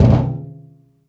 metal_drum_impact_thud_03.wav